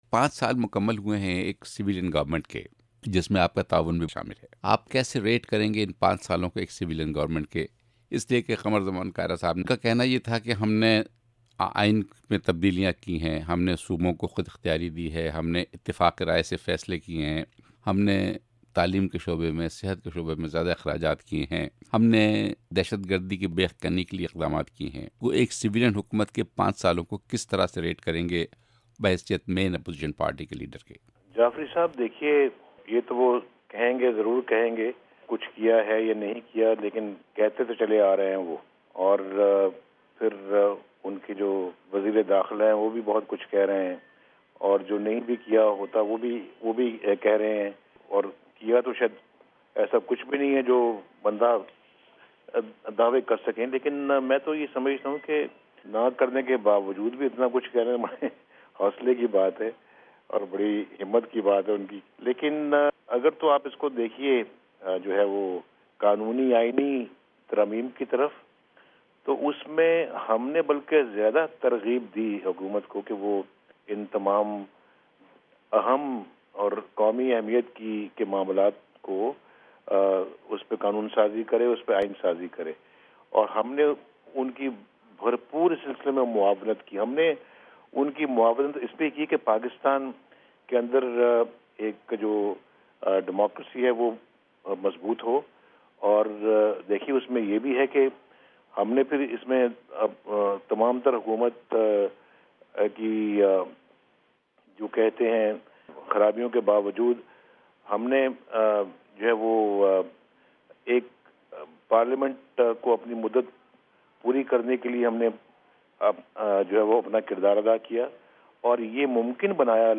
'وائس آف امریکہ' کی اردو سروس کو دیے جانے والے انٹرویو میں سابق وزیرِاعظم اور مسلم لیگ (ن) کے سربراہ نواز شریف نے دیگر جماعتوں کے ساتھ انتخابی اتحاد بنانے کا عندیہ دیا ہے